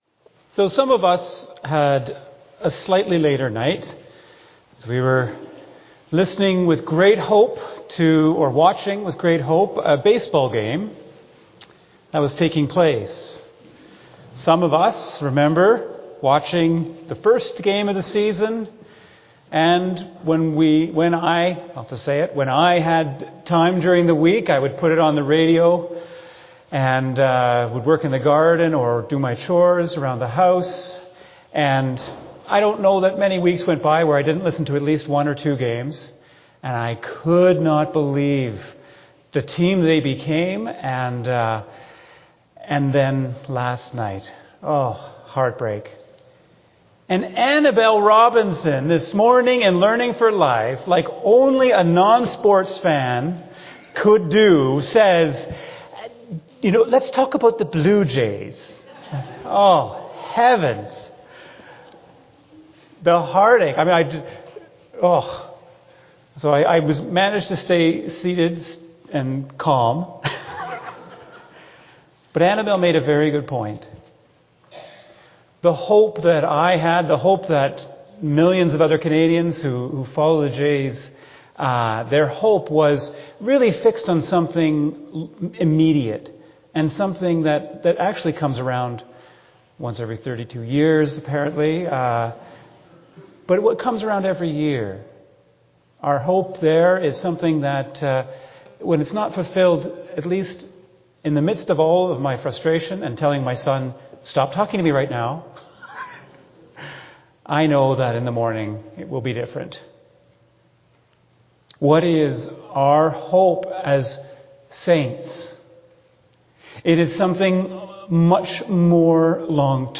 2025 Sermon November 2 2025